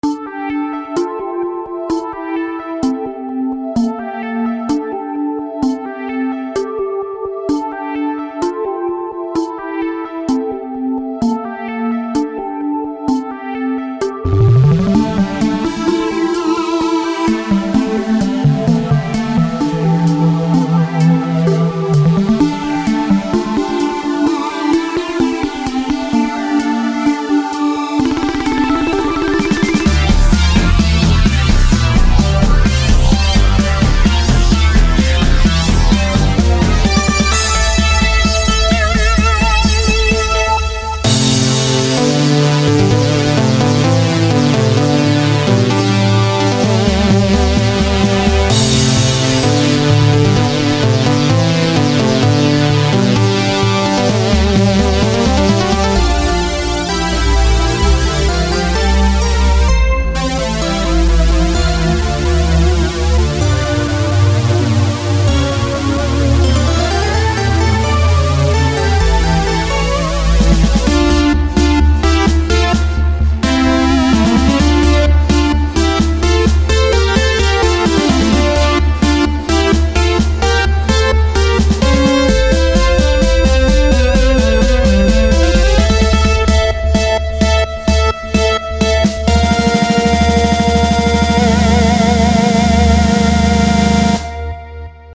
willow.intro.synthonly.mp3